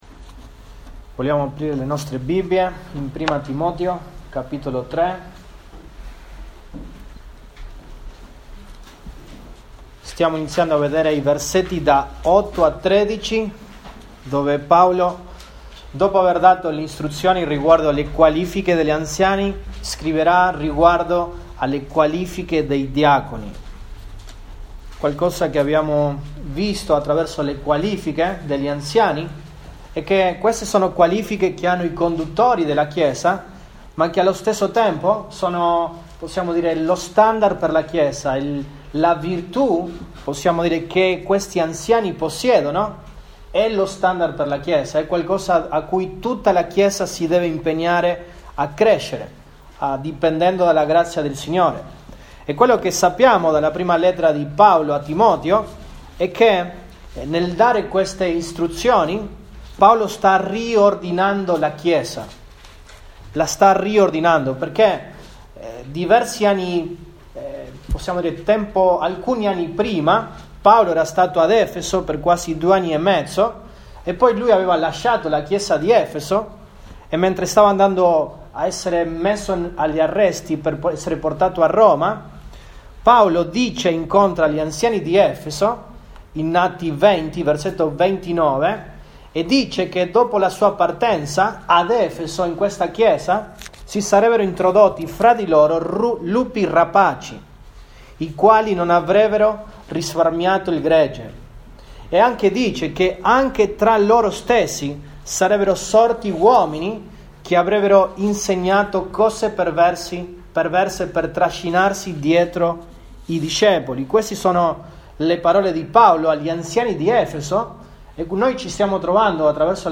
Apr 25, 2021 Le qualifiche dei diaconi, 1° parte MP3 Note Sermoni in questa serie Le qualifiche dei diaconi, 1° parte.